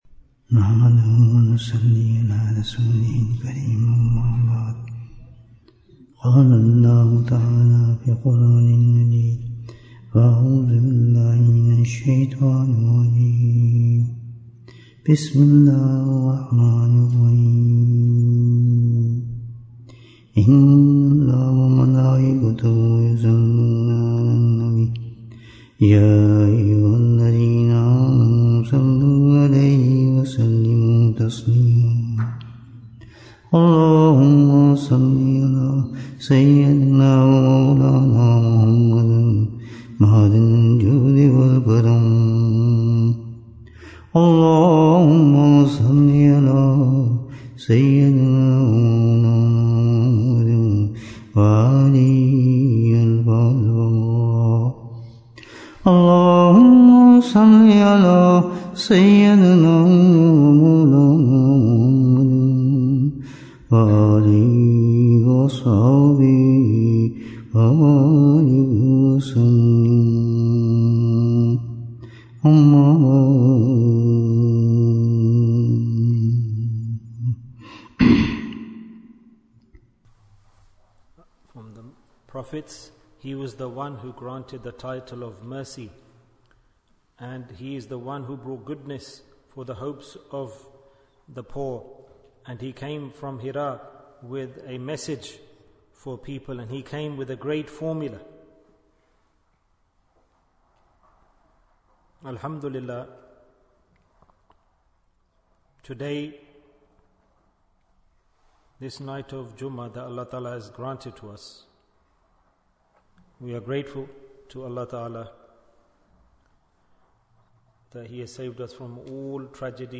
Welcome Rabbi-ul-Awwal Bayan, 56 minutes5th September, 2024